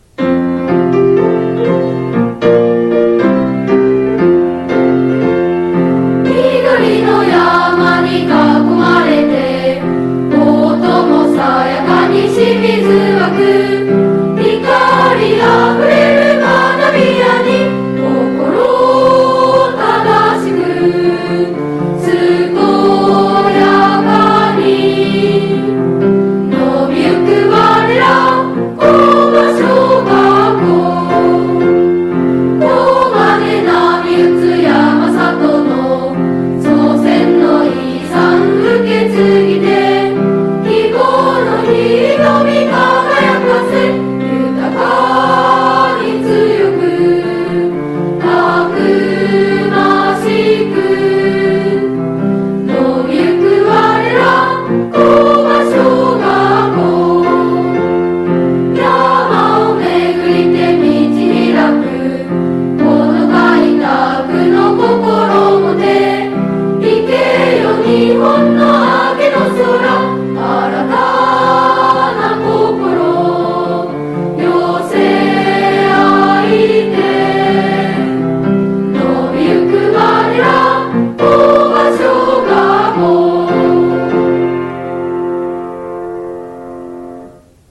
現行政区  旧行政区 No 小学校名  校歌楽譜・歌詞・概要  校歌音源（歌・伴奏） 　　備考
kobashokouka_gattshou.mp3